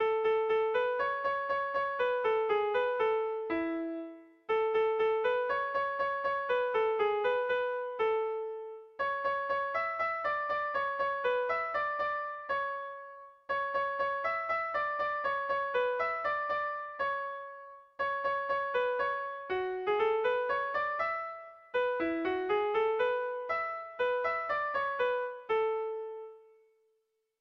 Mugerre < Lapurdi Beherea < Lapurdi < Euskal Herria
A1A2BBDE